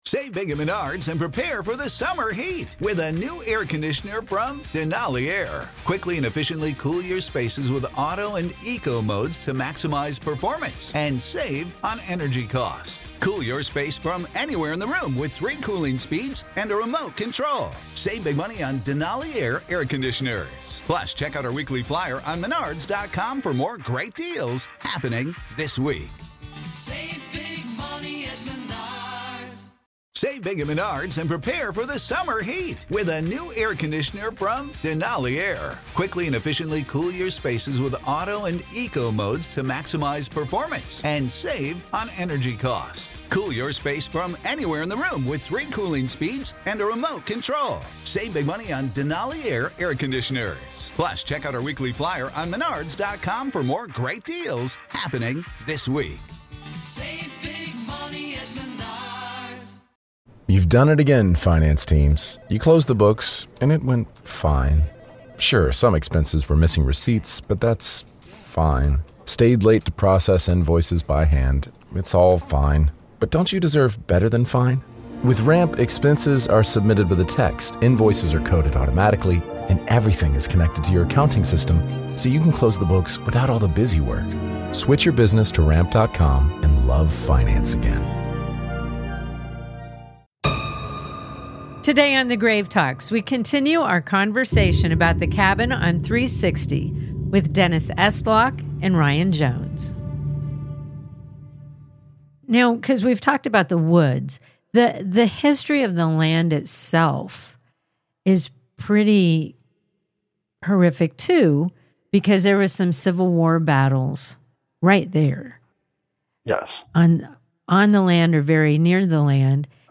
In this episode of The Grave Talks, Part Two of our conversation